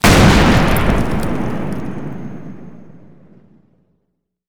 Index of /server/sound/weapons/explosive_m67
m67_explode_1.wav